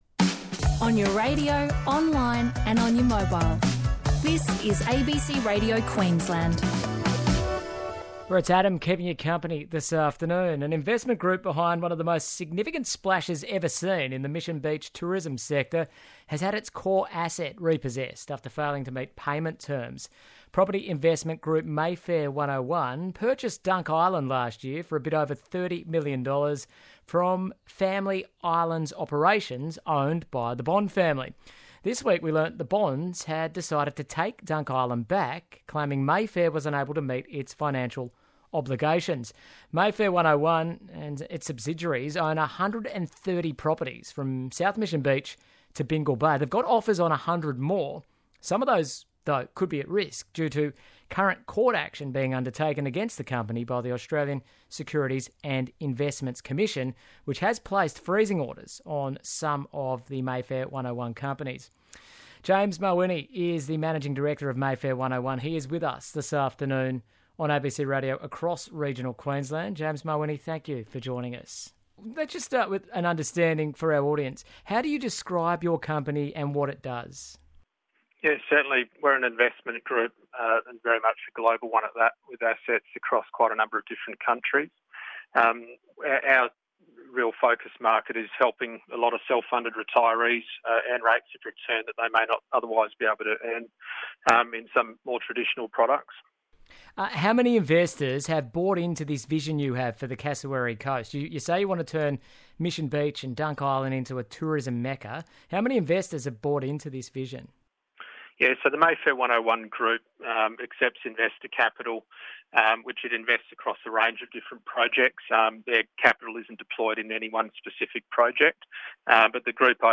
on ABC Drive Radio Queensland